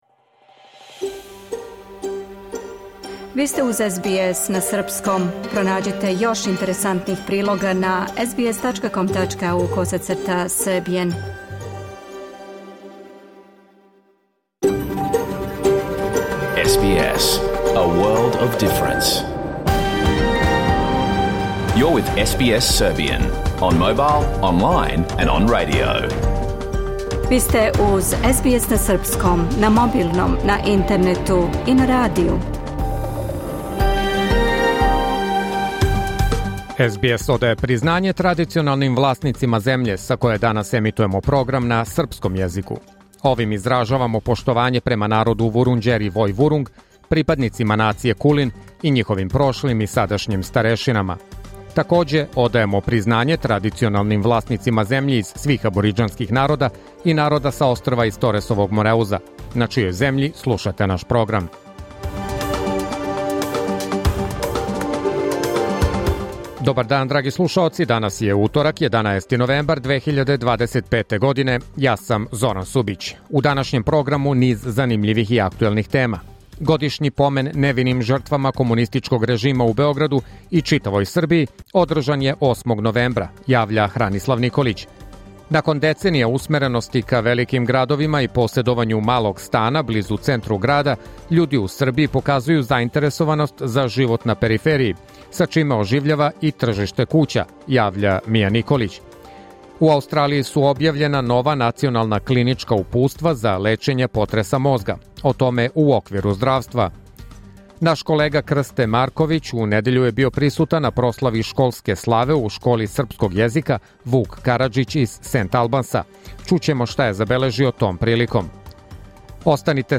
Програм емитован уживо 11. новембра 2025. године